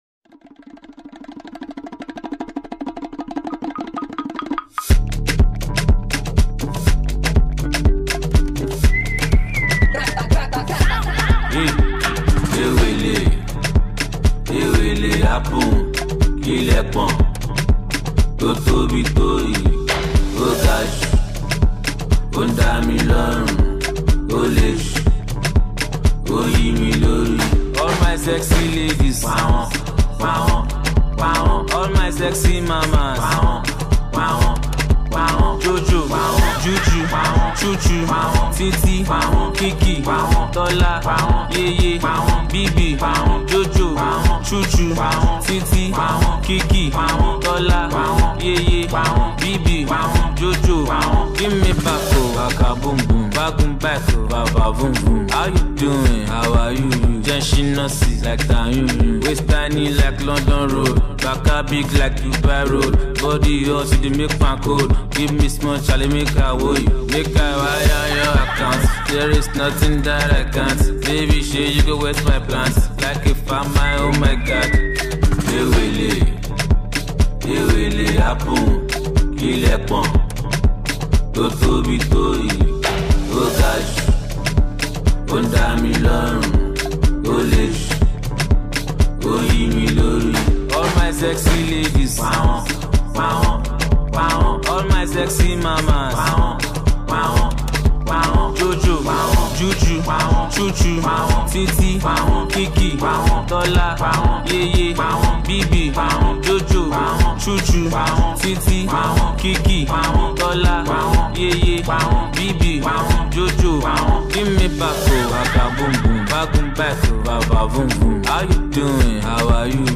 a new dance song for the ladies
potential street banger